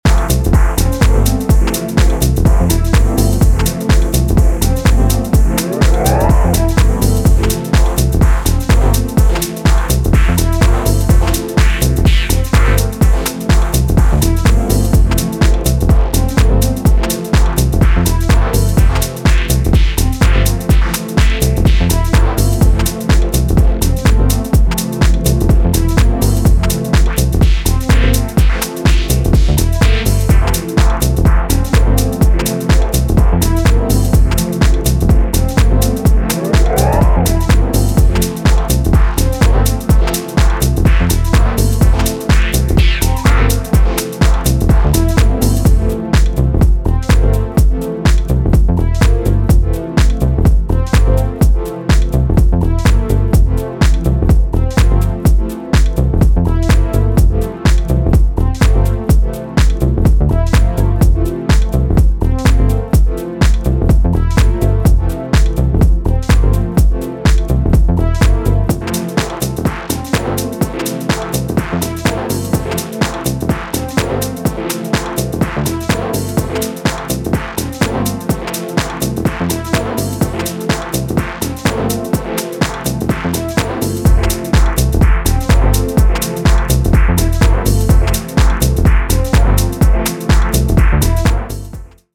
コミカルで浮遊感のあるリフとバウンシーなボトム、時折トビを効かせて酩酊に誘う
今回は全体的にダークでトリッピーなムードが際立っており、深い時間に良質な溜めを産んでくれるでしょう。